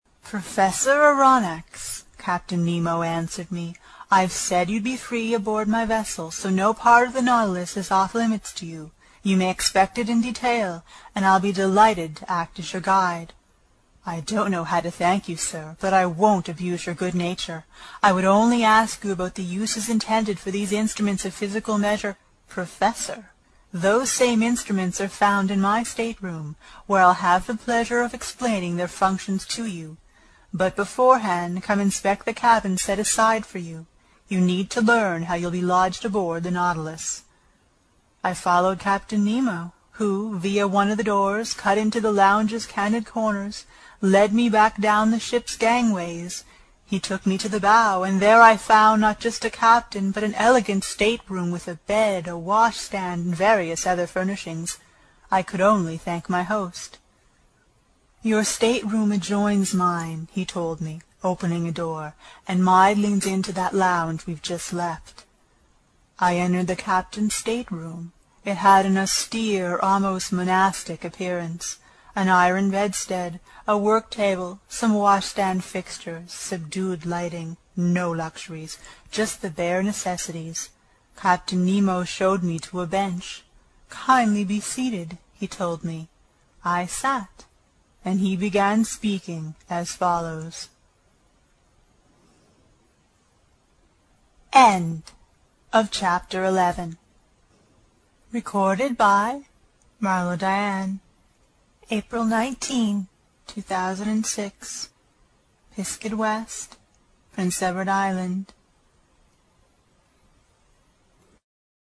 英语听书《海底两万里》第166期 第11章 诺第留斯号(17) 听力文件下载—在线英语听力室
在线英语听力室英语听书《海底两万里》第166期 第11章 诺第留斯号(17)的听力文件下载,《海底两万里》中英双语有声读物附MP3下载